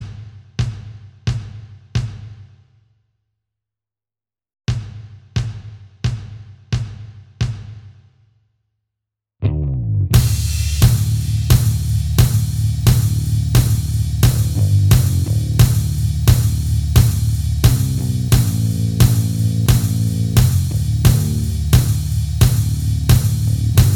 Minus Lead Guitar Rock 3:46 Buy £1.50